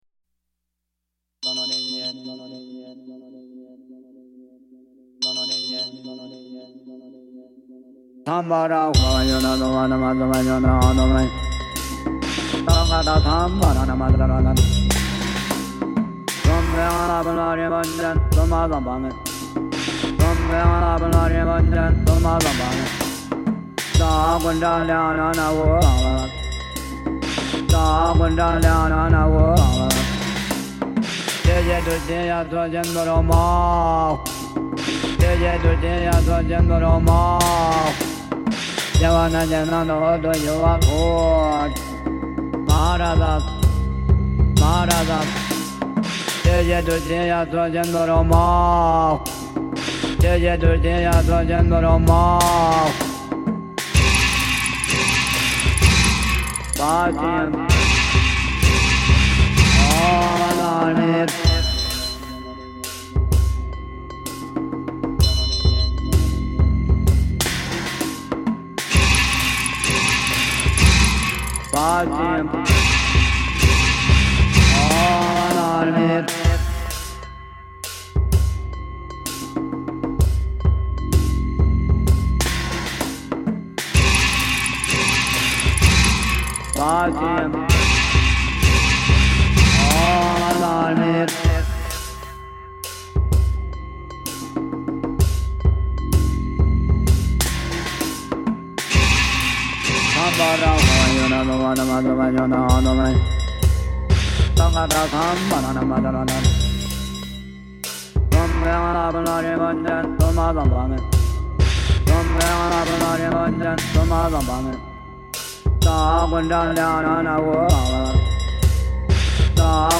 Monks chanting at Leh reimagined